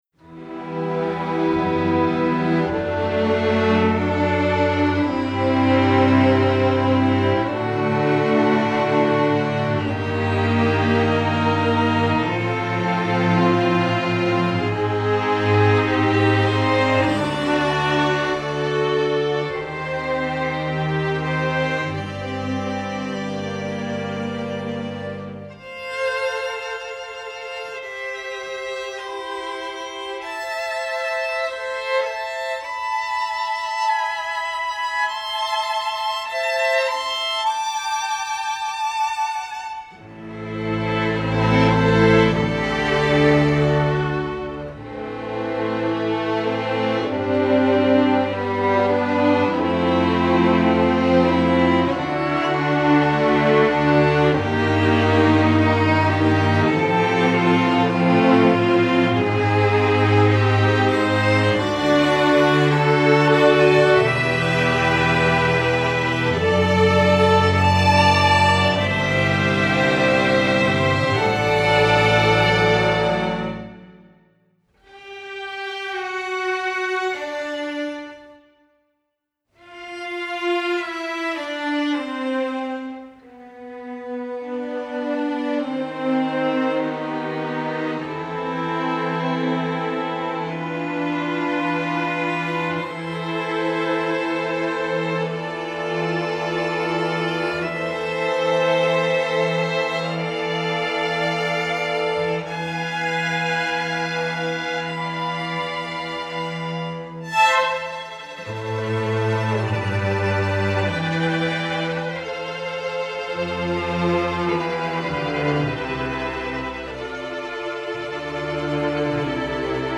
Instrumentation: string orchestra (full score)
instructional